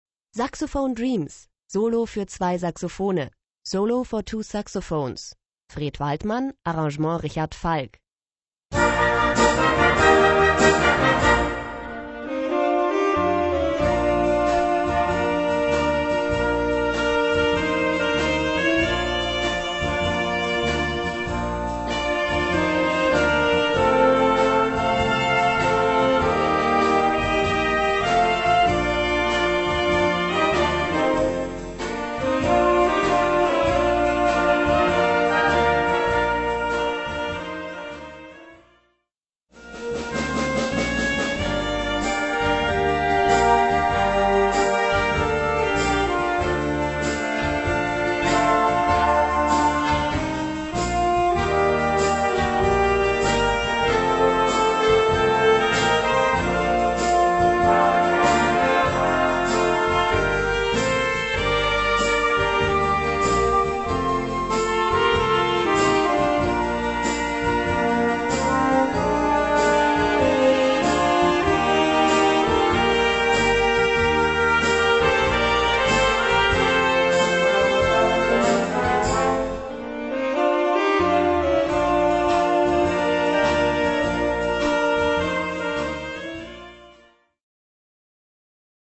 Gattung: Solo für 2 Saxophone und Blasorchester
Besetzung: Blasorchester
(Alt- und Tenorsaxophon oder 2 Altsaxophone)